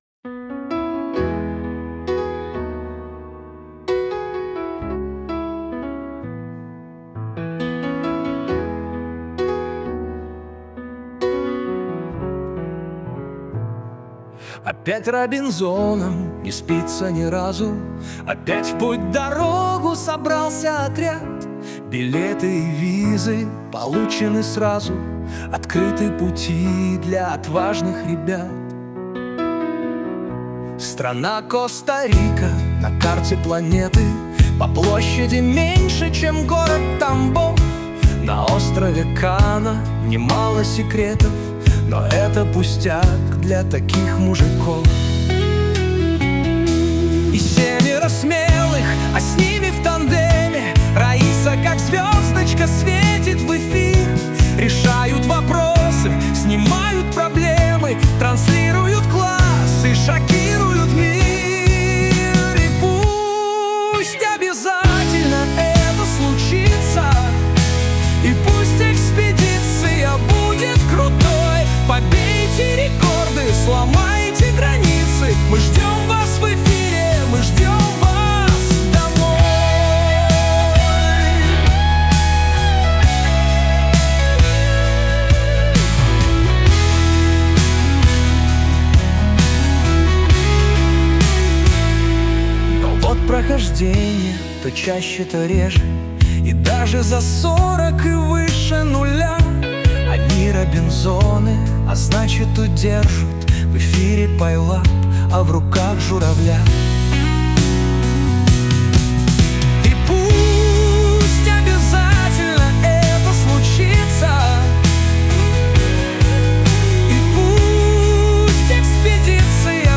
песню